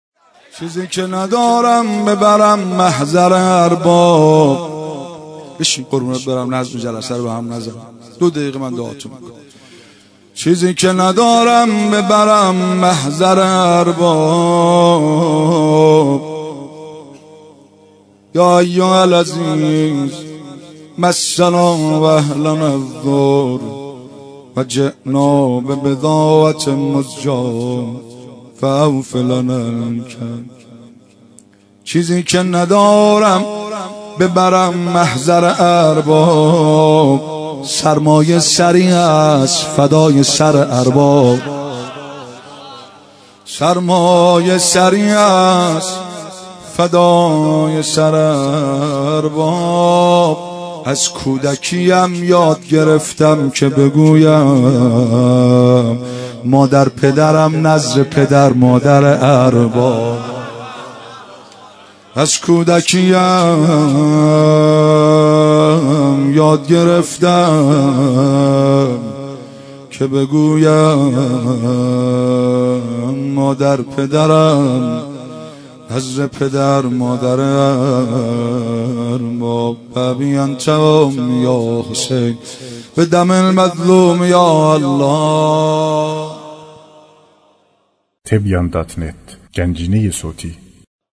دانلود مداحی فاطمیه 1392